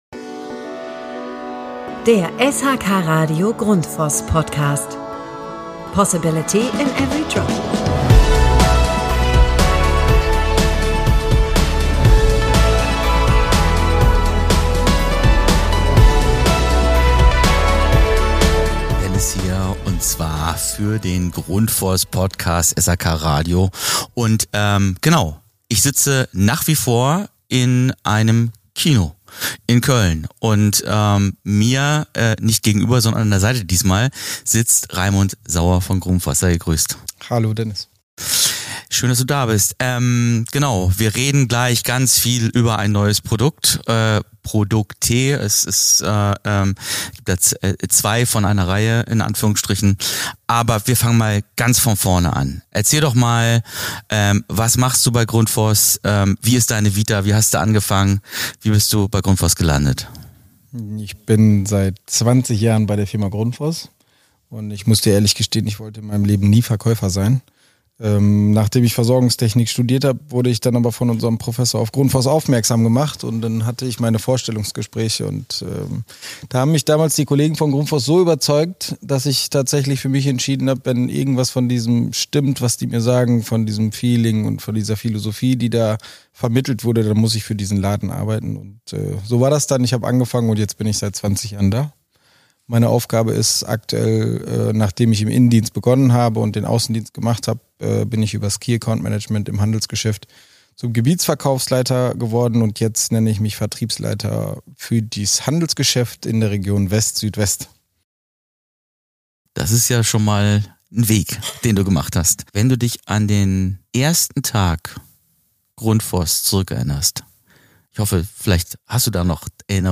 Ein Gespräch über Technologie, Nähe zum Handwerk und echte Begeisterung für ein Produkt, das mehr kann als nur pumpen.